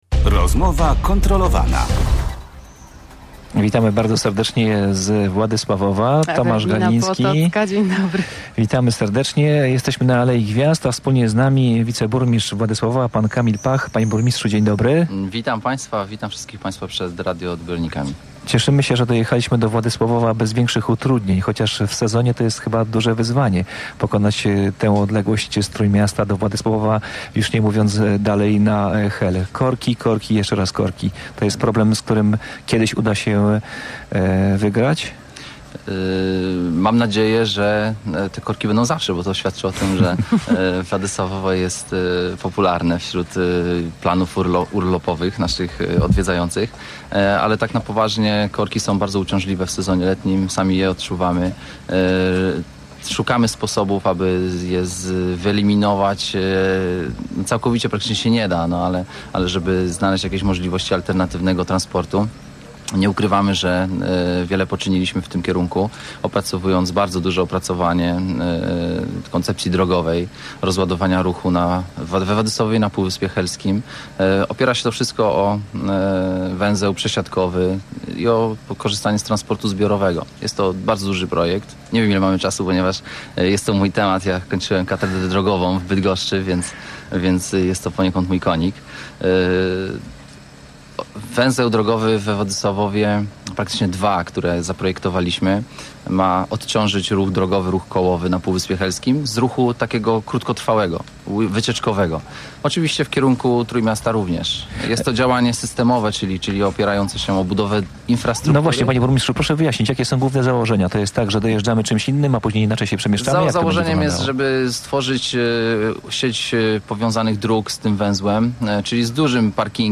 Gościem Rozmowy Kontrolowanej był Kamil Pach, zastępca burmistrza Władysławowa.